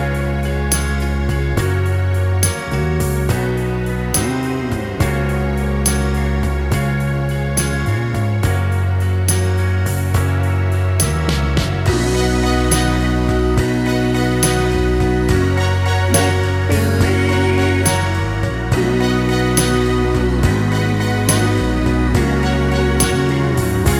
One Semitone Down Pop (1980s) 3:04 Buy £1.50